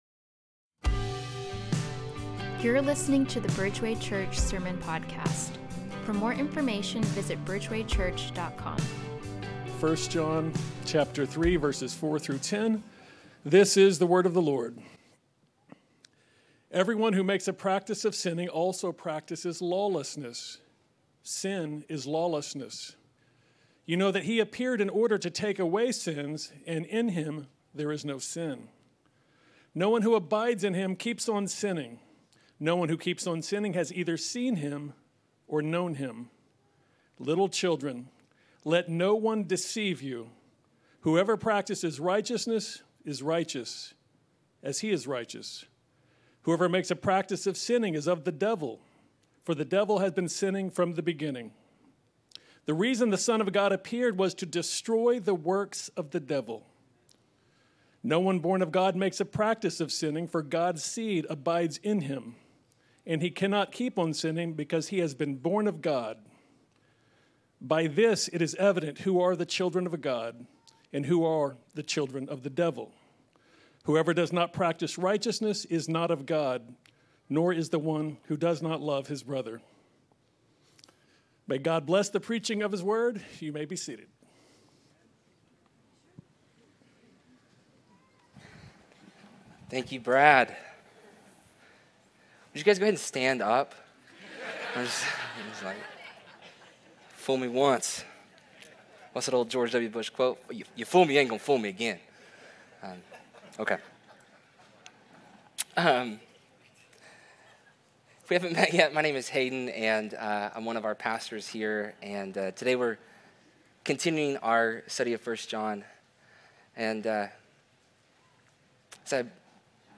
Bridgeway Church Sermons